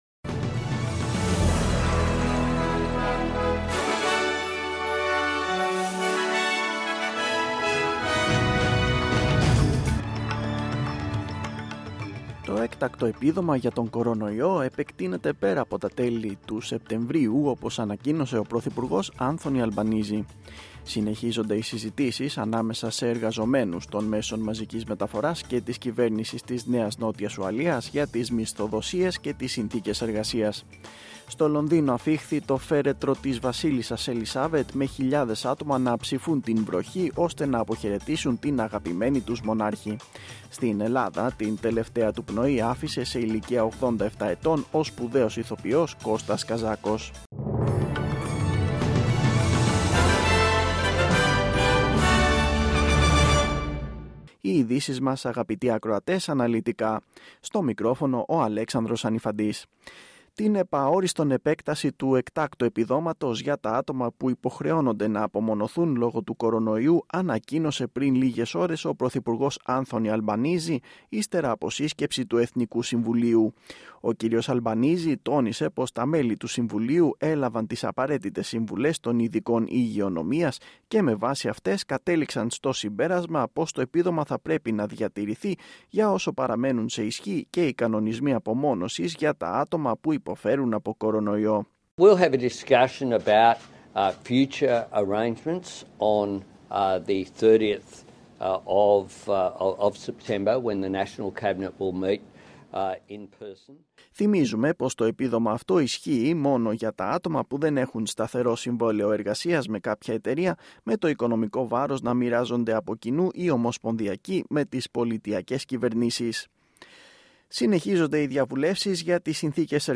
News in Greek. Source: SBS / SBS Radio